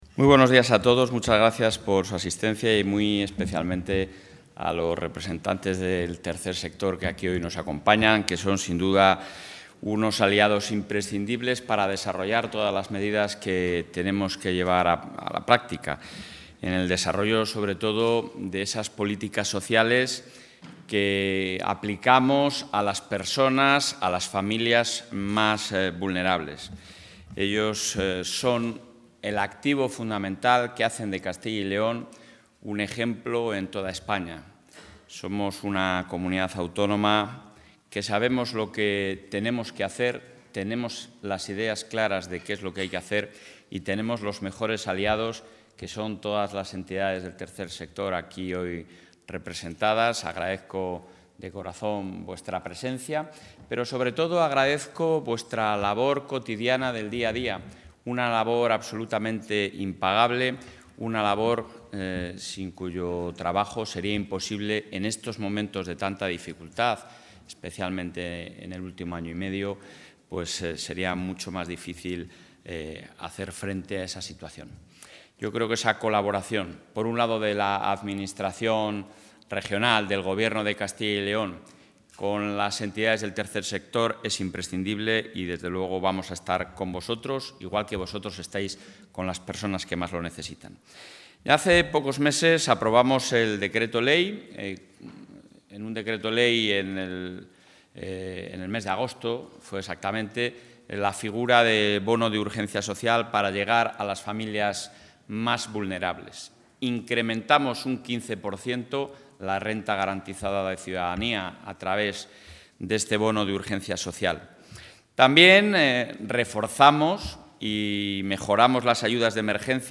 Intervención del presidente de la Junta.
El presidente de la Junta de Castilla y León, Alfonso Fernández Mañueco, ha presentado hoy, ante representantes del Tercer Sector, una nueva línea de ayudas a la hipoteca para familias con rentas medias y bajas. Su objetivo es reforzar la protección de las familias frente a la subida de precios y el incremento de los tipos de interés.